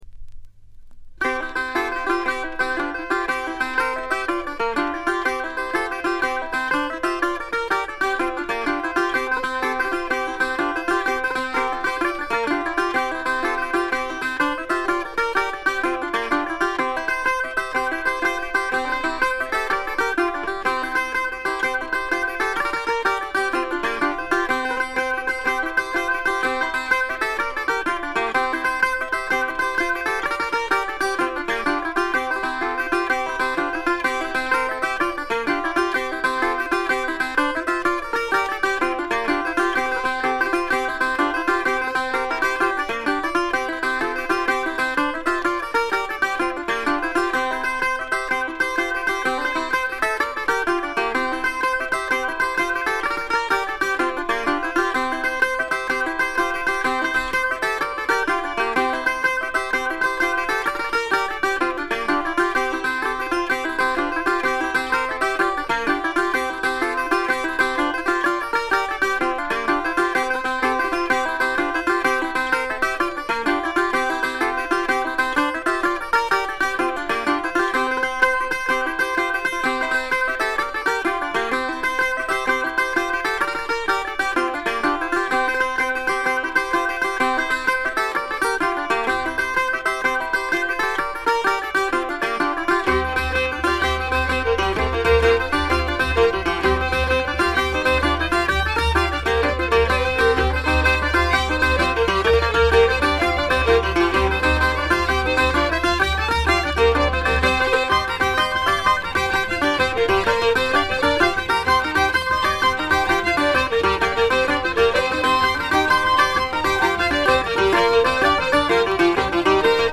ごくわずかなノイズ感のみ。
売りである哀愁のアイリッシュムードもばっちり。
アイリッシュ・トラッド基本盤。
試聴曲は現品からの取り込み音源です。
Steel Guitar